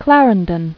[Clar·en·don]